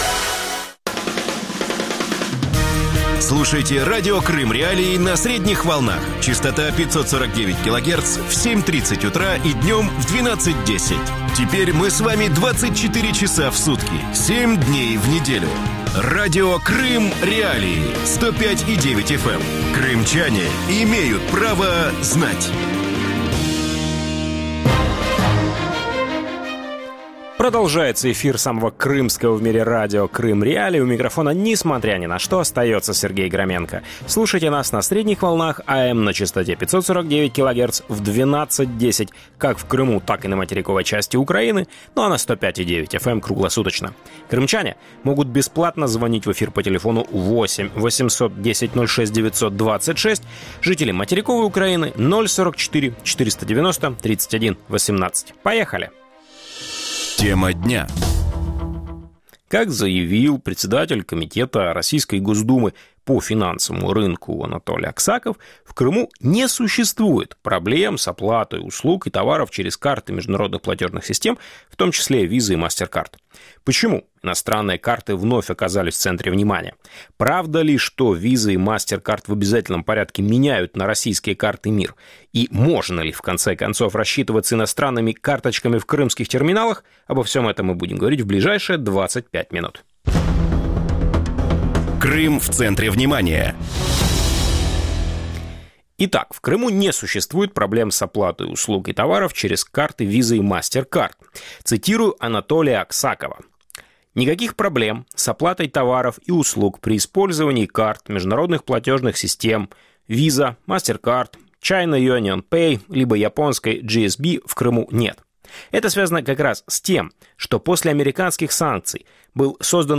ток-шоу